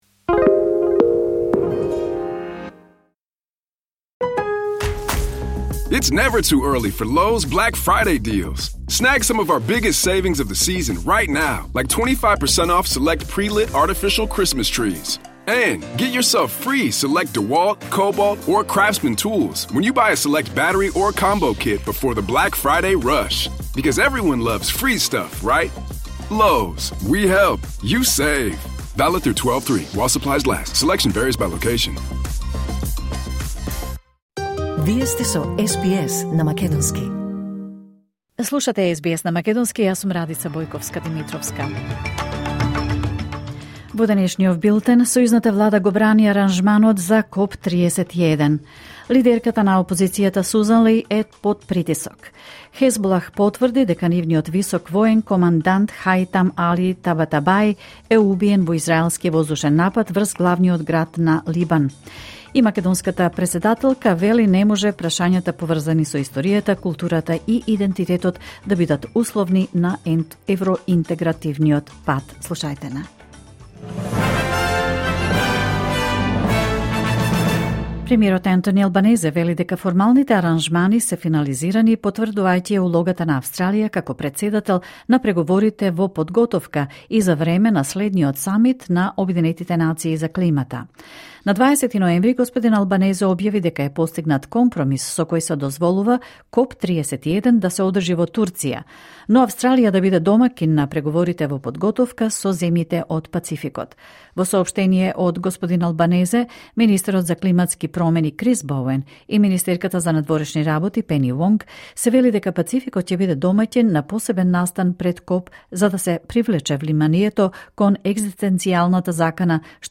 Вести на СБС на македонски 24 ноември 2025